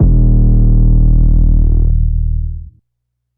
TS - 808 (7).wav